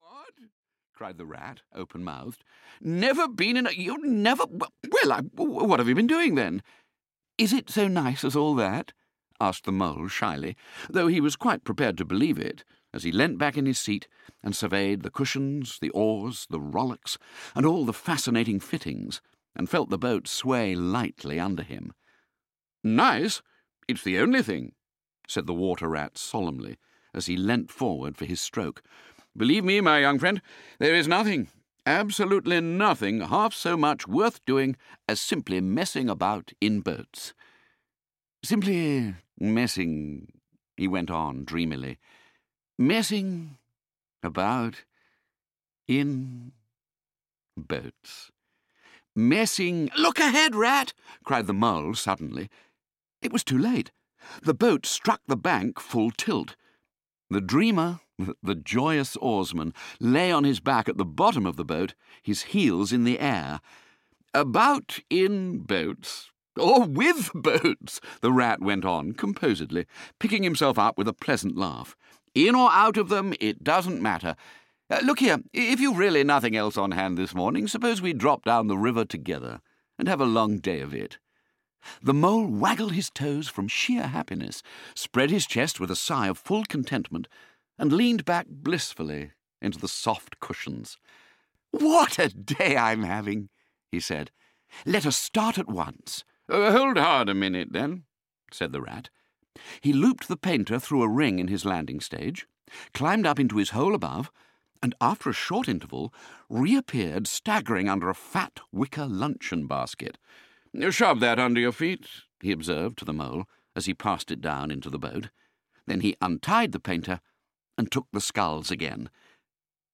Audio kniha
Ukázka z knihy
• InterpretMartin Jarvis